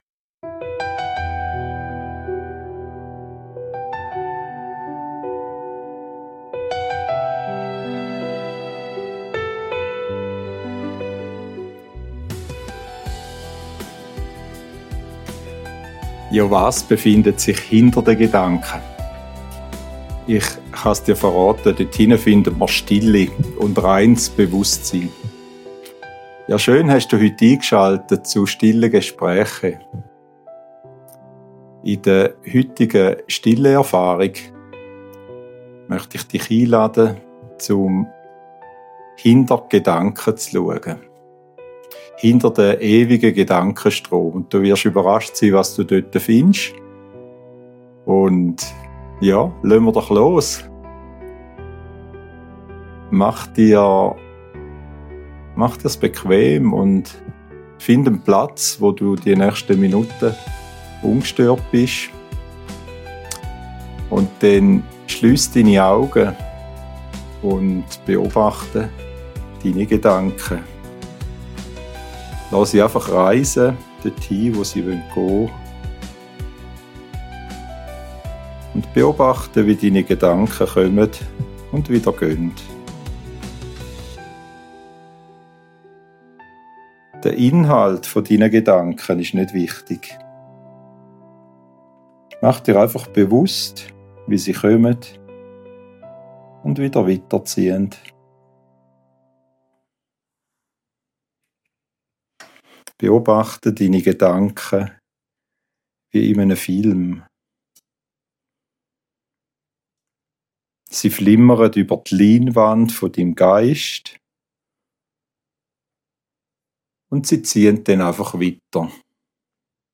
Geführte Stille-Übung – Finde das NICHTS in dir - Innere Ruhe
Schweizerdeutsch gesprochen.